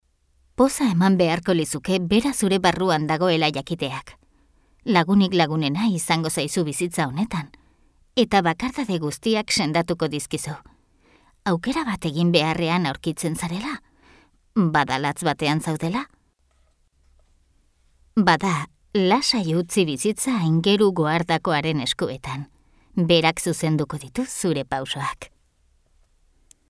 Basque female voice overs